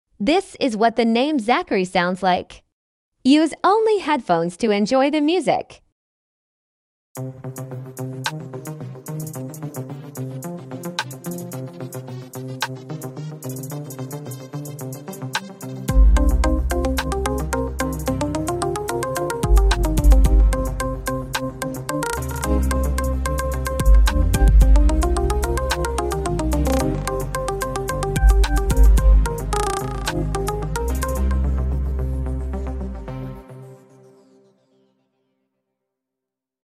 Midi art